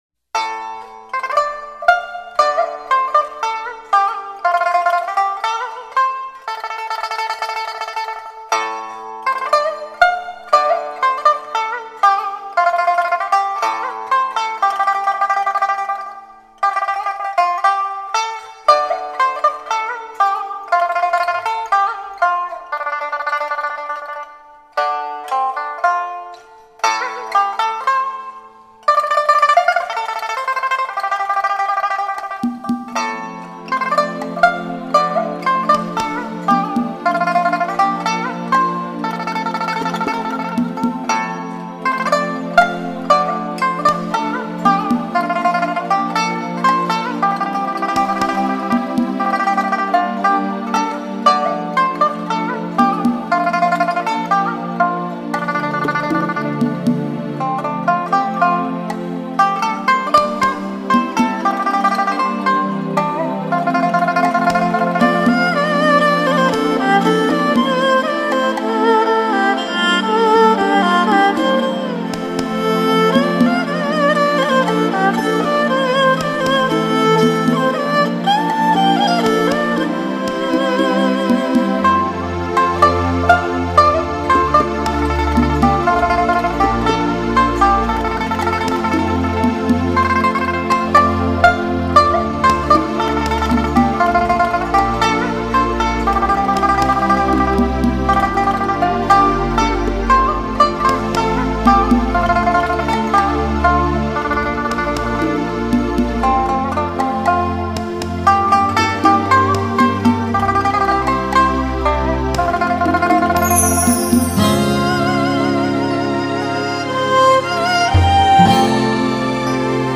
以較少的配器表達空靈深摯的感情；編以心跳般的律動節奏、娓娓傾訴的婉約旋曲